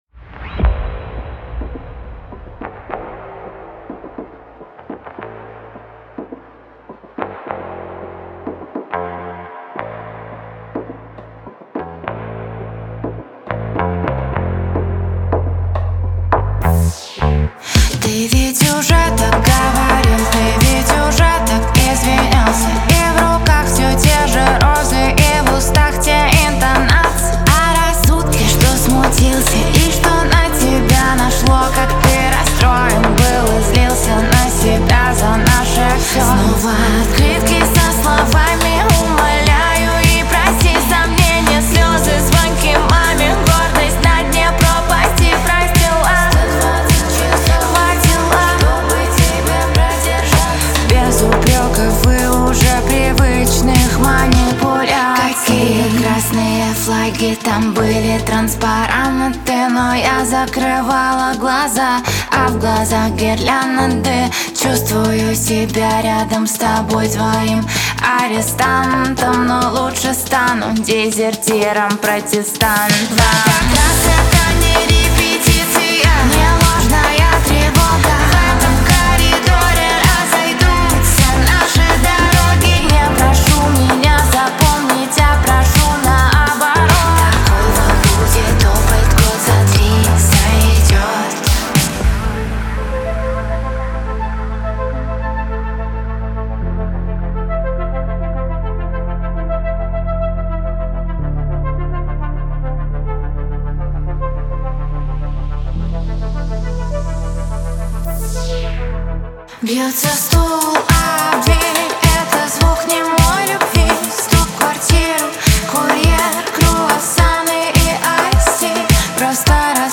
Русская Поп-Музыка